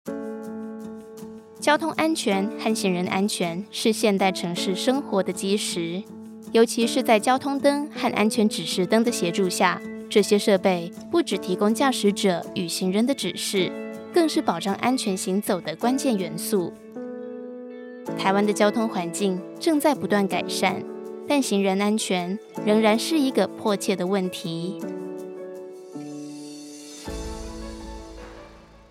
E-learning
I am a Taiwanese Mandarin speaker.
I am a young and mature female vocal artist with experience in various areas of voice over work.